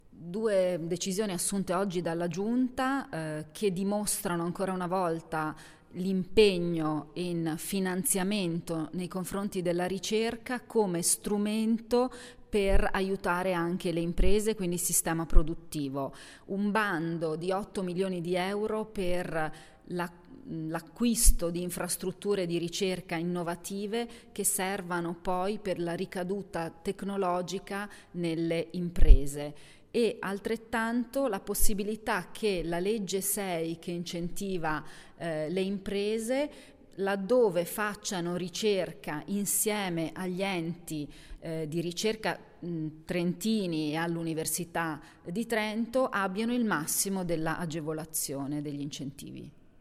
l'assessora_Ferrari.mp3